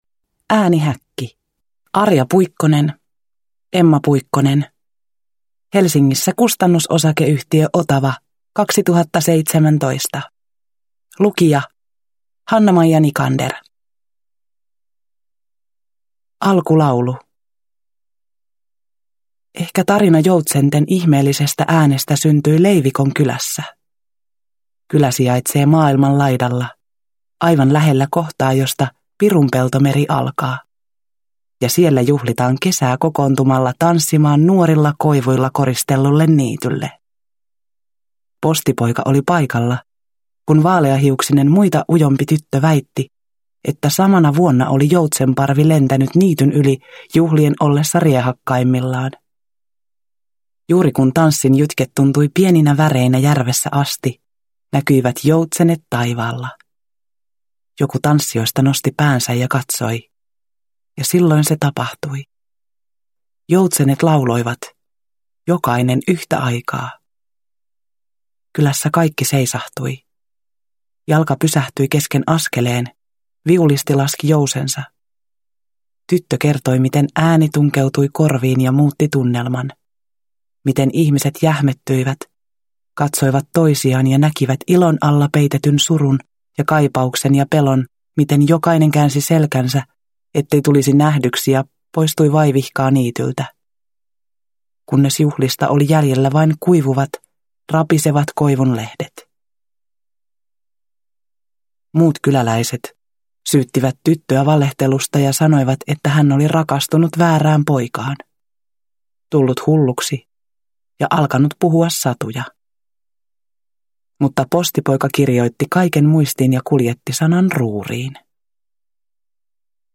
Äänihäkki – Ljudbok – Laddas ner